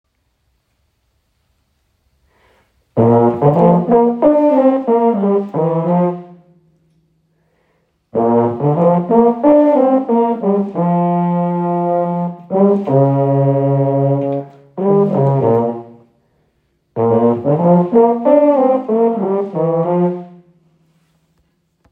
Baritoni
Baritoni.m4a